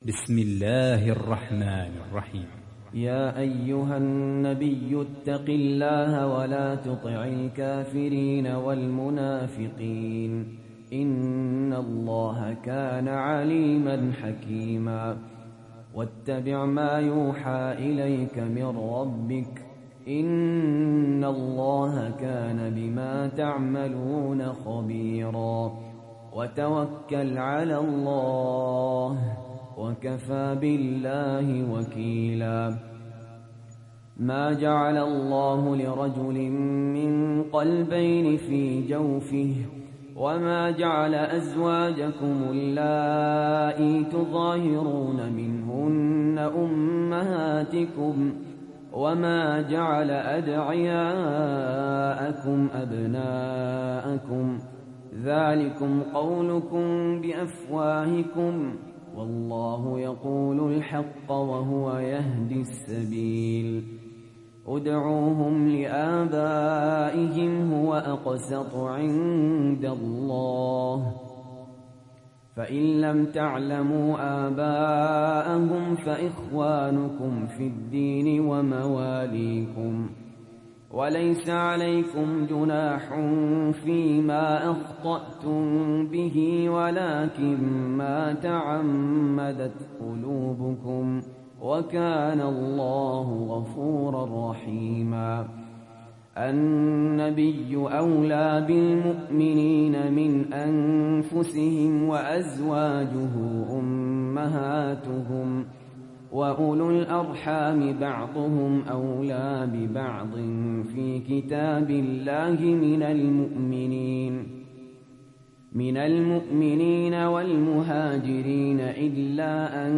تحميل سورة الأحزاب mp3 بصوت سهل ياسين برواية حفص عن عاصم, تحميل استماع القرآن الكريم على الجوال mp3 كاملا بروابط مباشرة وسريعة